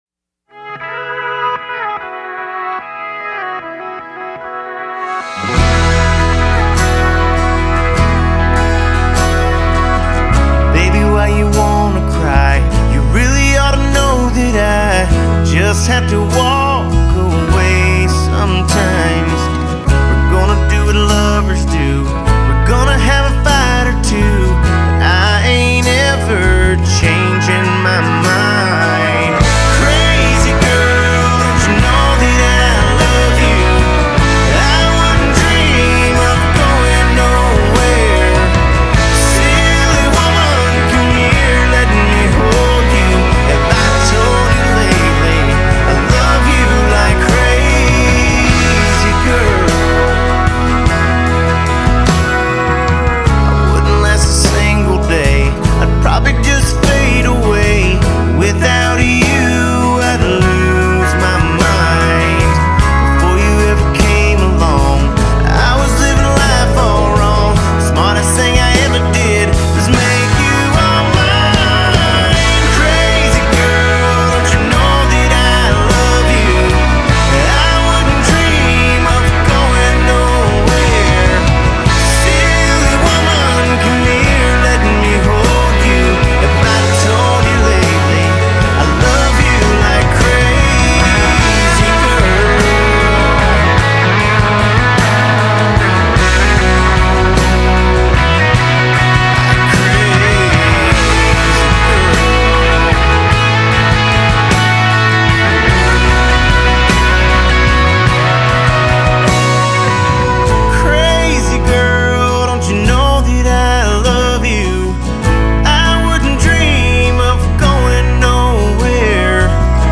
Genre: Country-Rock